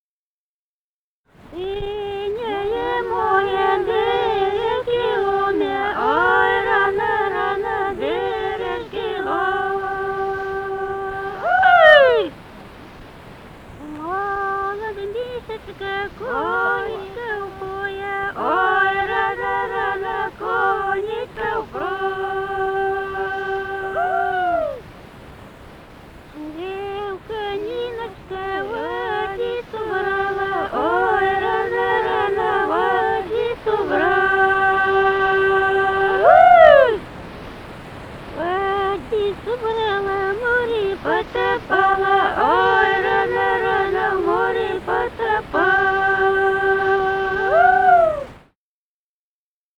Музыкальный фольклор Климовского района 011. «Синее море бережки ломе» (карагодная на Пасху).